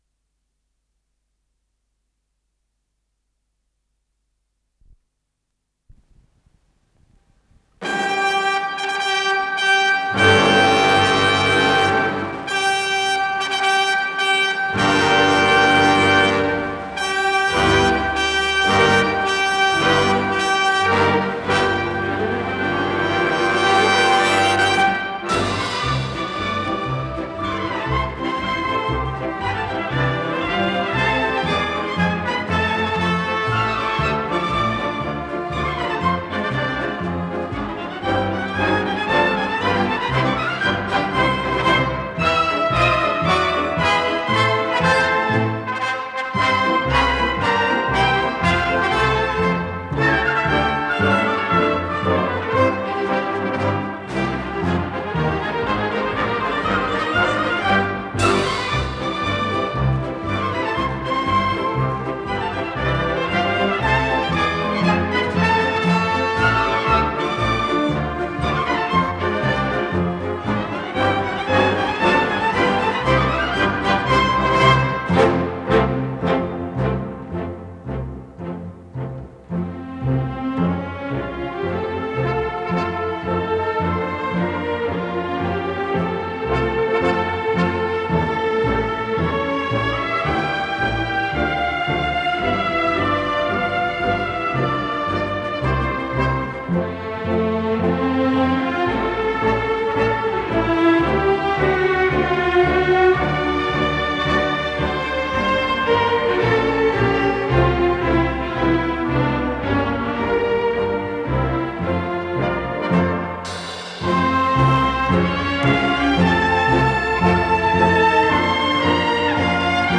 с профессиональной магнитной ленты
ИсполнителиЭстрадный оркестр Всесоюзного радио и Центрального телевидения
Дирижёр - Юрий Силантьев